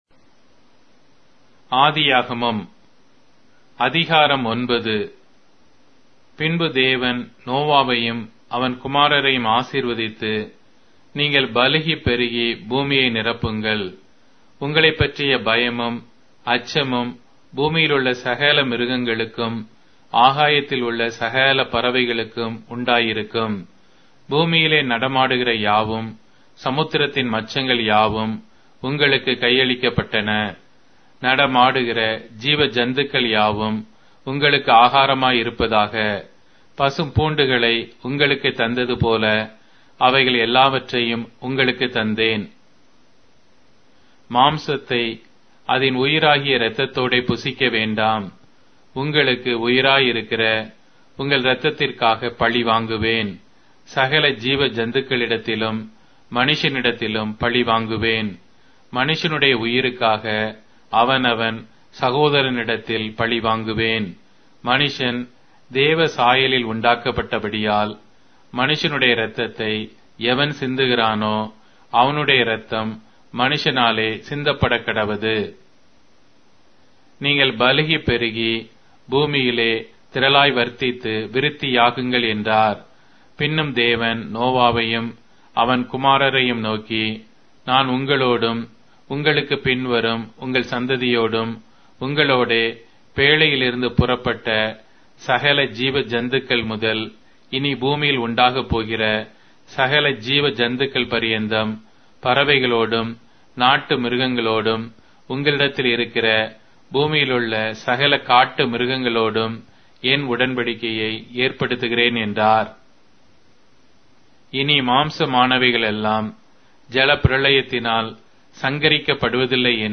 Tamil Audio Bible - Genesis 35 in Ervhi bible version